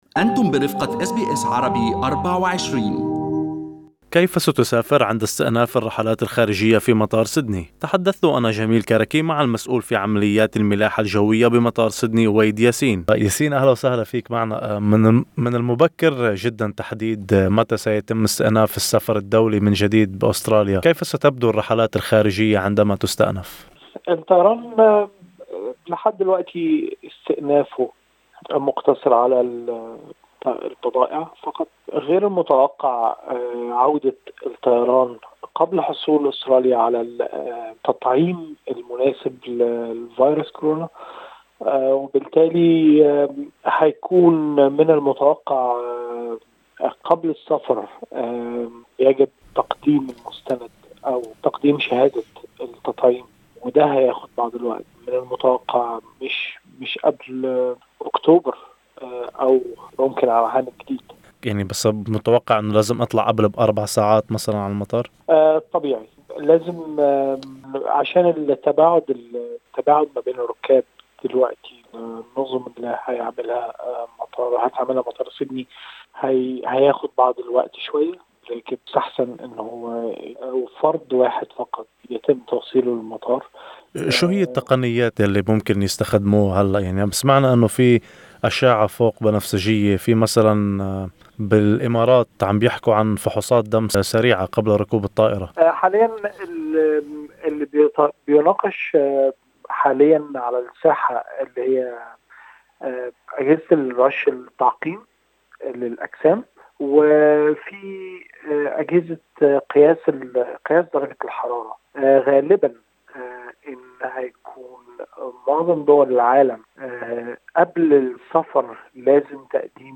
للتعرف على المزيد يرجى الاستماع إلى اللقاء الصوتي أعلاه.